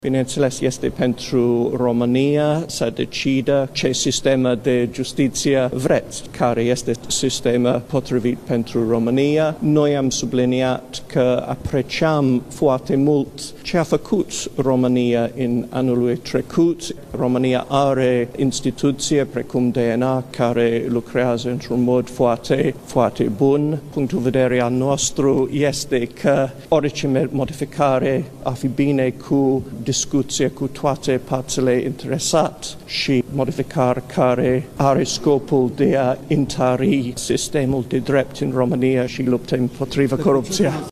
Modificările legilor justiţiei trebuie făcute după consultări cu toate instituţiile implicate şi trebuie să aibă ca scop întărirea luptei anticorupţie şi a statului de drept, a declarat, miercuri, ambasadorul Marii Britanii în România, Paul Brummell, în urma discuţiilor cu ministrul Justiţiei, Tudorel Toader.